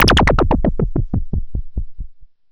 Synth Fx Stab 04.wav